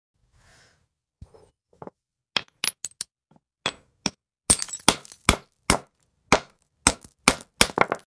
Cinematic ASMR — cracking a smooth jade banana with hammer & chisel, revealing its vivid crystal core